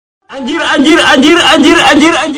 Efek suara Anjir anjir anjir
Kategori: Suara viral